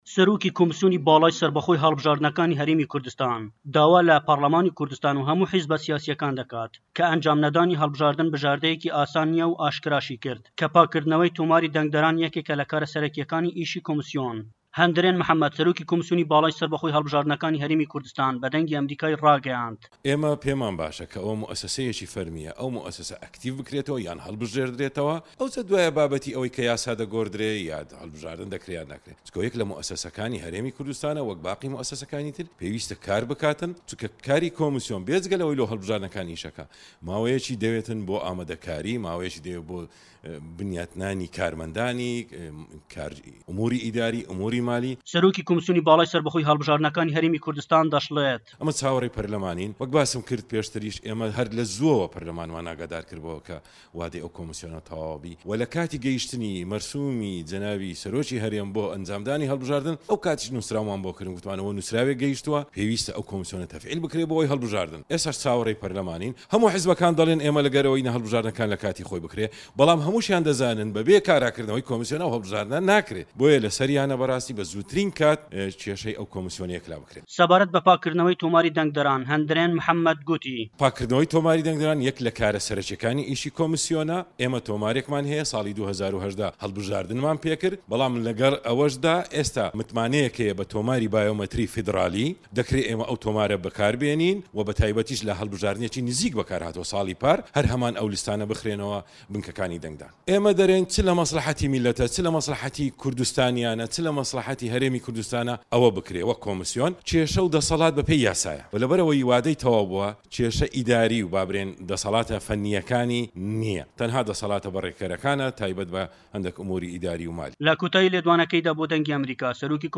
ڕاپۆرتی پەیامنێر